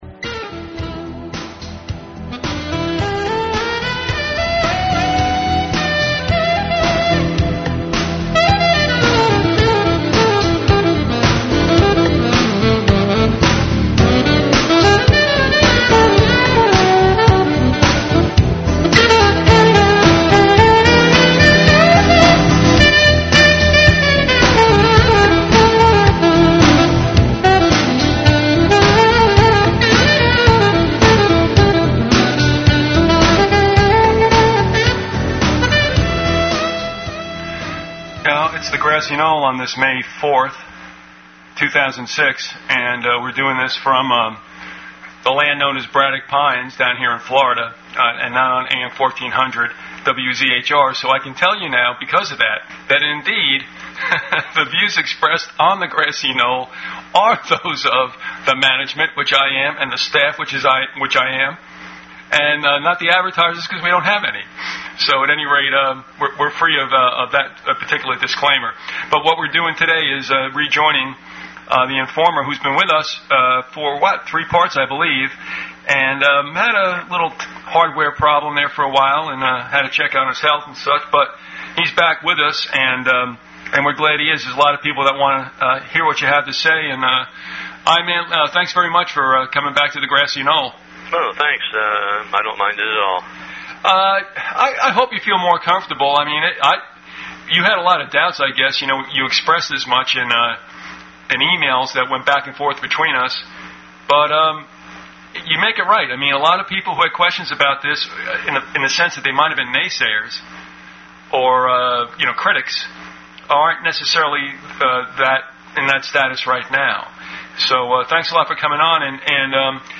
please send an email to the archivist and be sure to reference the title of the interview.